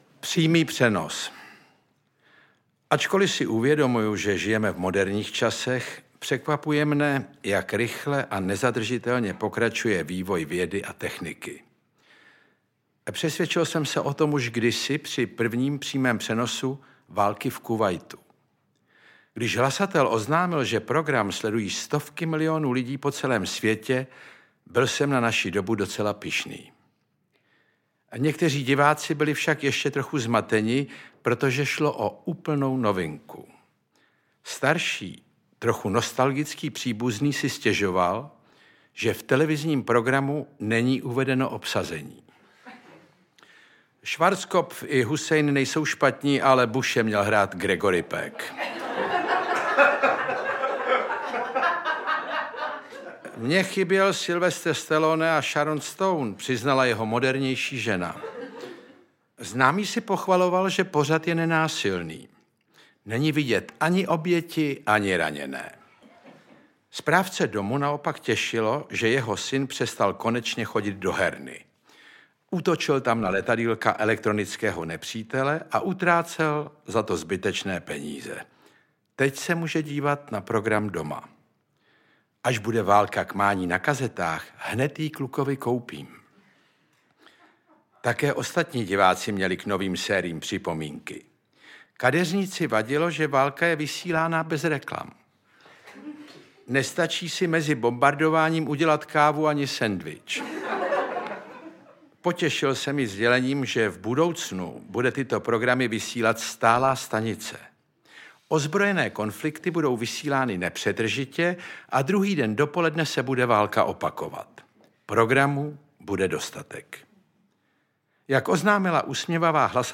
Fantom televize audiokniha
Audiokniha Fantom televize - obsahuje humorné čtení z knih Ivana Krause.
Ukázka z knihy